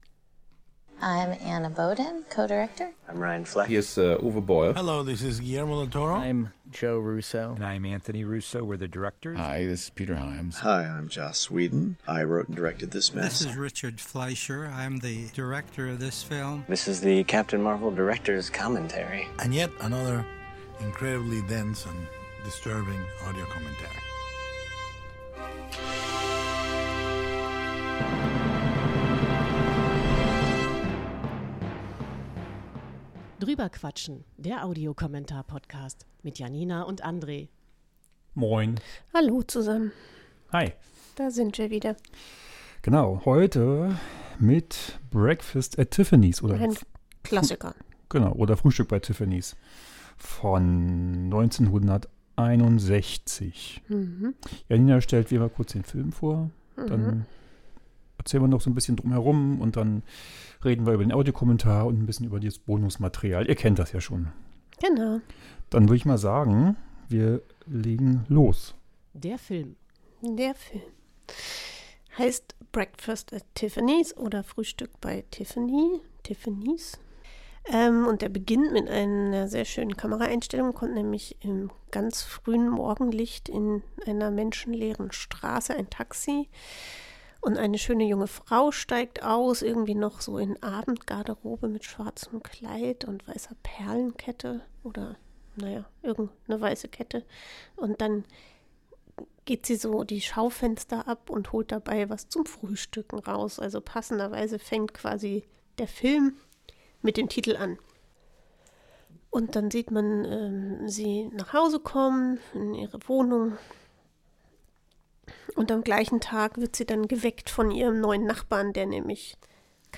Ein Audiokommentar